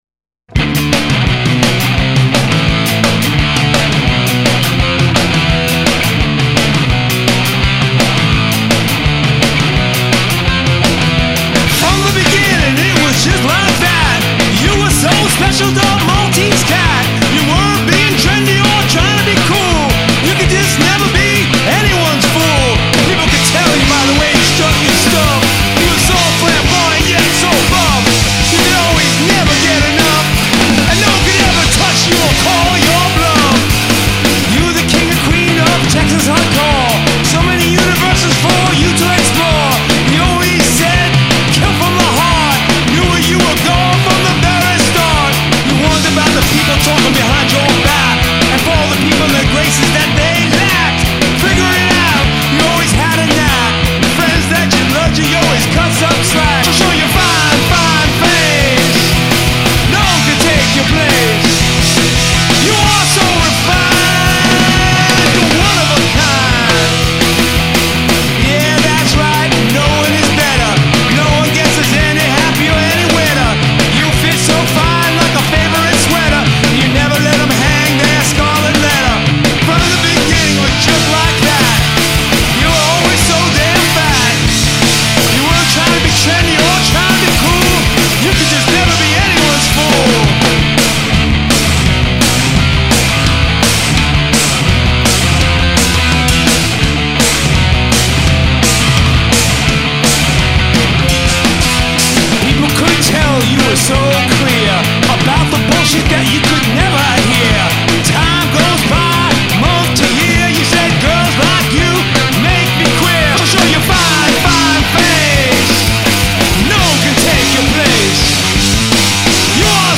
(Punk / Hardcore U.S., since 1980 !)
enregistré aux Cyclone Studios, New York